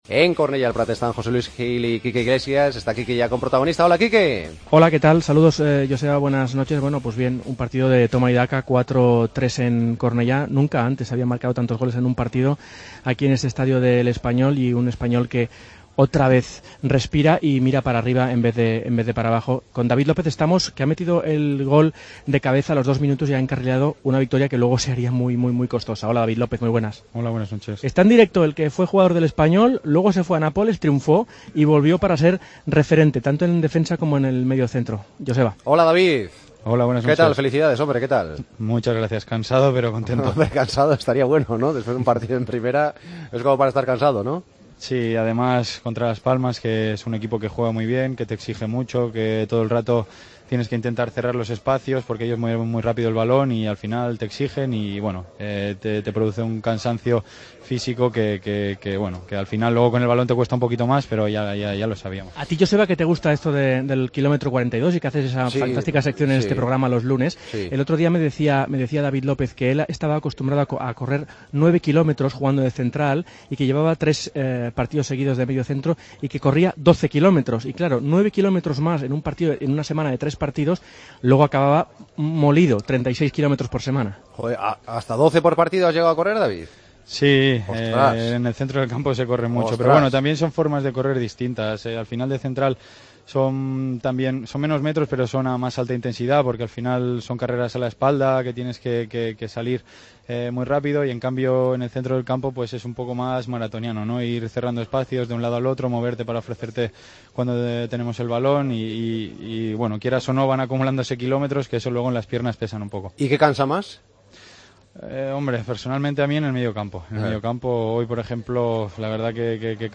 AUDIO: Hablamos con el autor del primer gol del Espanyol ante Las Palmas.